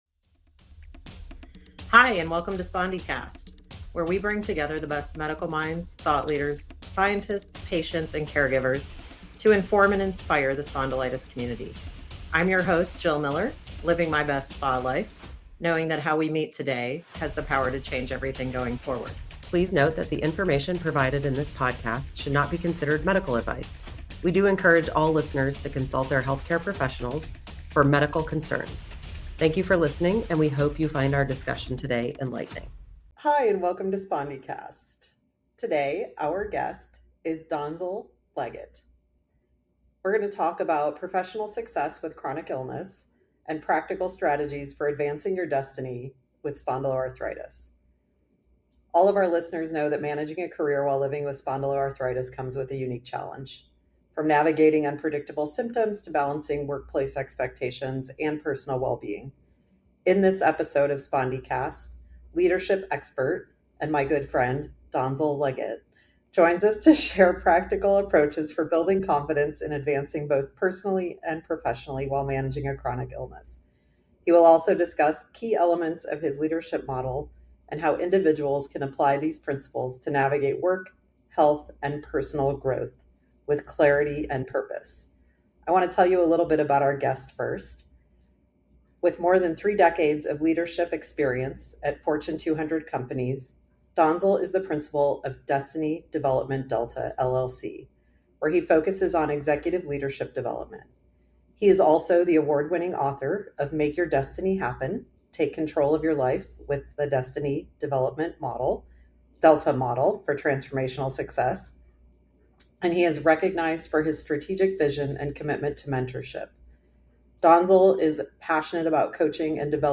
Every week, we bring you in-depth conversations with the leading experts in the field, who will share their knowledge and experience on topics related to the diagnosis, treatment, and management of this complex condition.